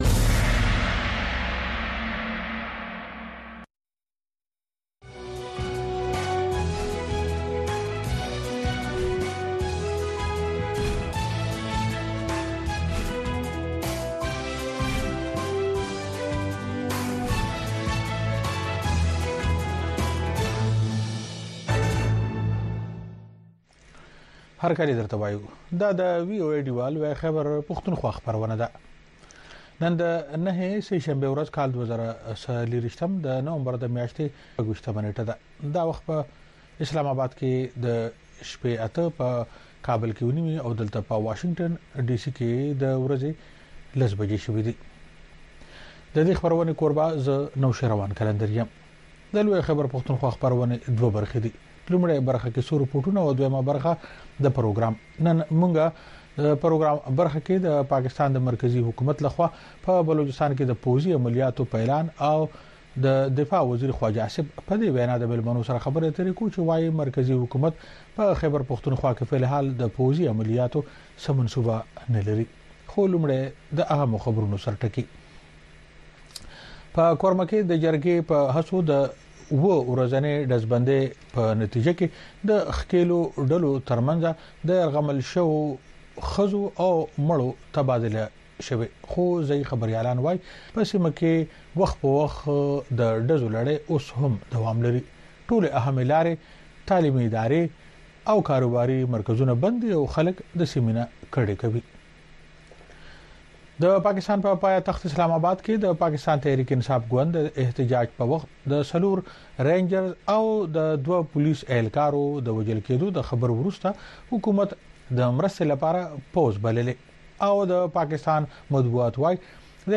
د وی او اې ډيوه راډيو خبرونه چالان کړئ اؤ د ورځې د مهمو تازه خبرونو سرليکونه واورئ.